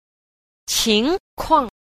6. 情況 – qíngkuàng – tình huống